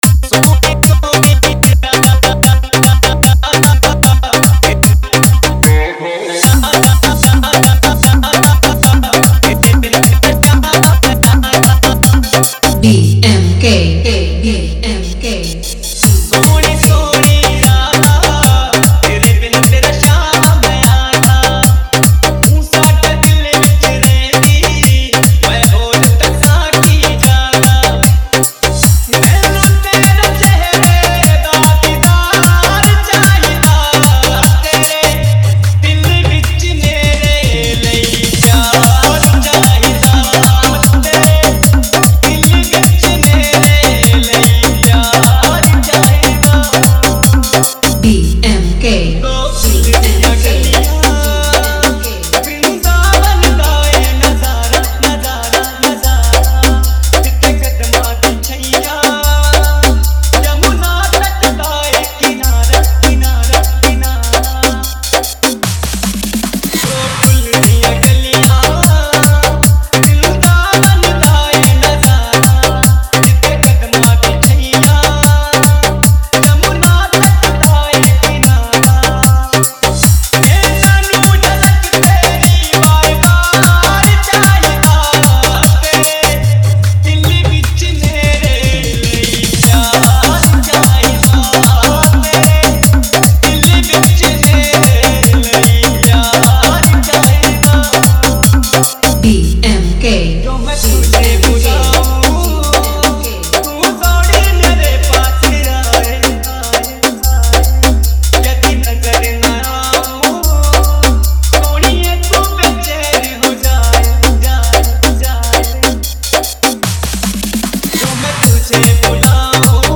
Best Dj Remix Song 2025, Full Dance Bhakti Dj Remix